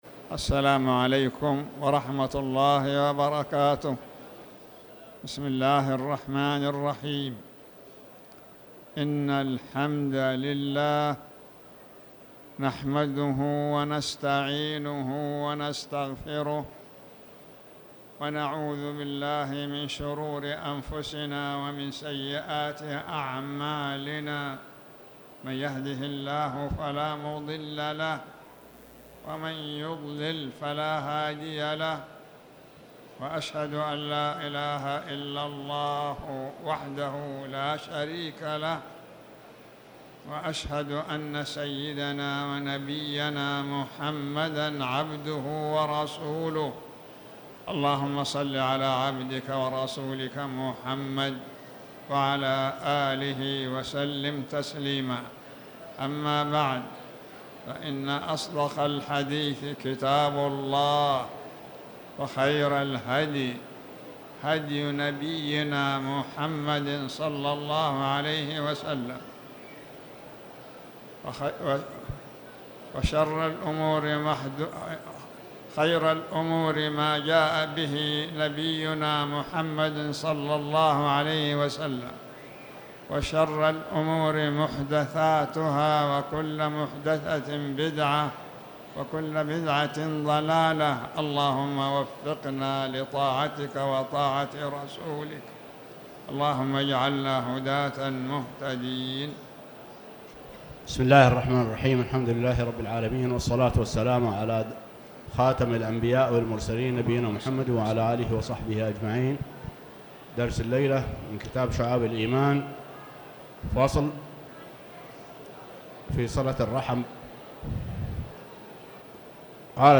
تاريخ النشر ٢٣ ربيع الثاني ١٤٤٠ هـ المكان: المسجد الحرام الشيخ